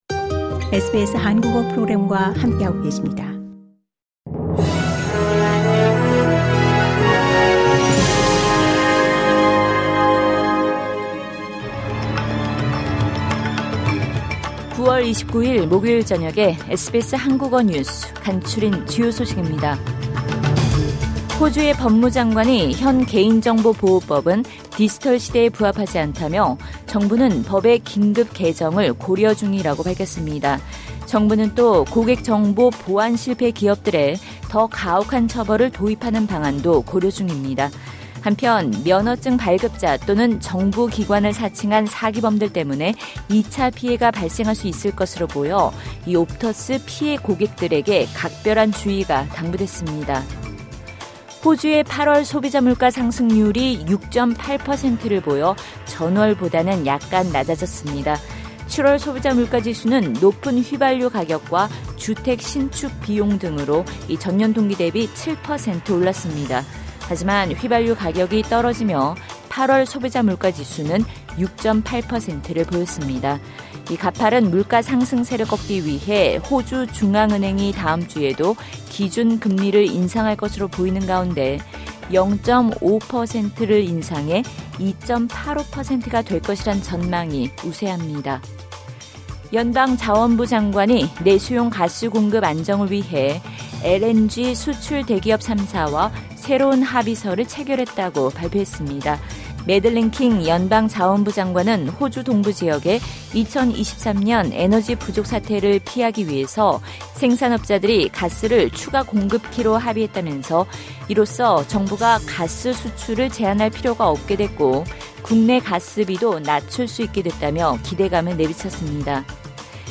SBS 한국어 저녁 뉴스: 2022년 9월 29일 목요일
2022년 9월 29일 목요일 저녁 SBS 한국어 간추린 주요 뉴스입니다.